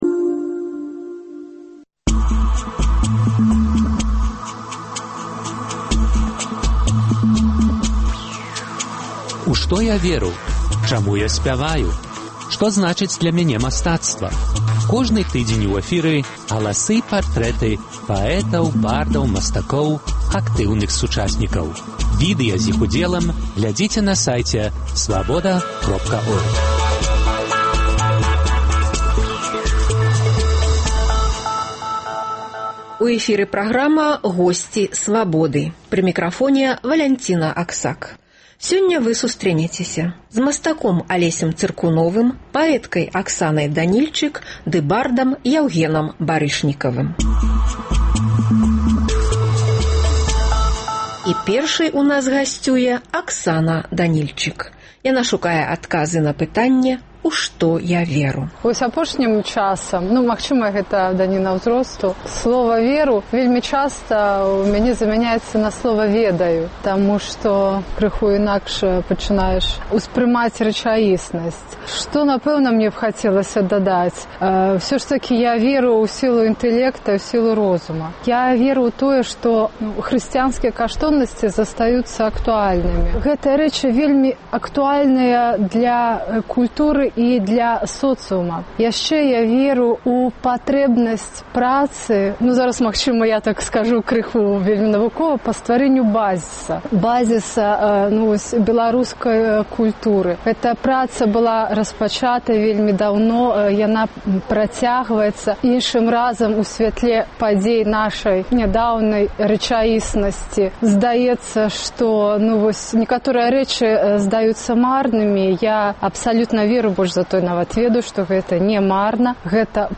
Галасы і партрэты паэтаў, бардаў, мастакоў, актыўных сучасьнікаў.